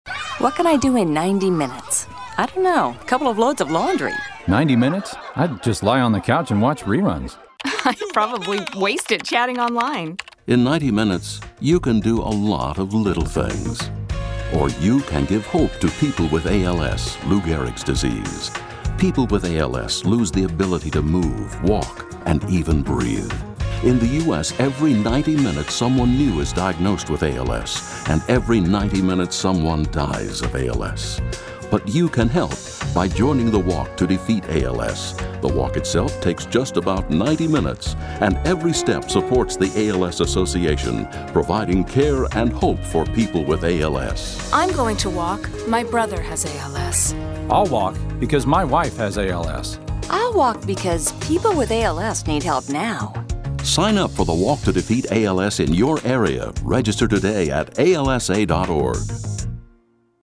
Walk To D’Feet ALS� in 90 Minutes Radio PSA